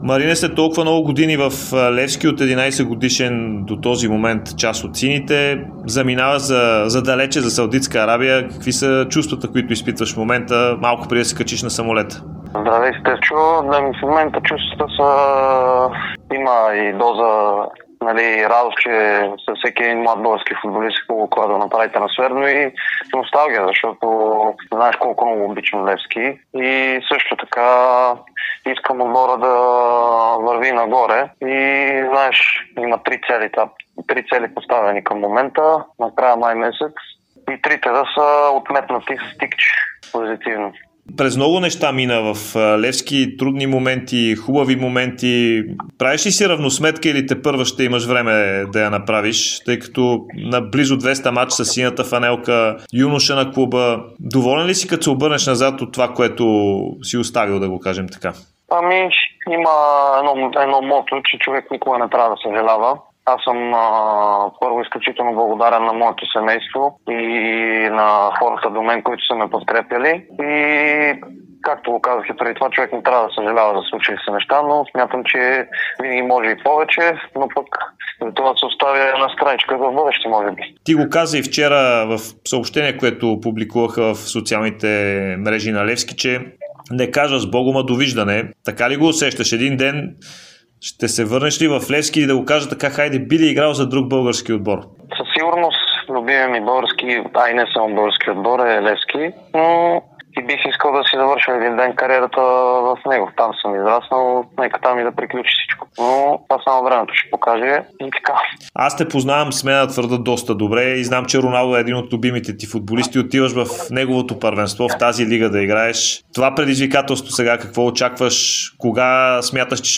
Националът Марин Петков, който снощи бе продаден от Левски на саудитския Ал Таавун говори за Дарик радио и DSPORT минути преди да отлети към арабския свят.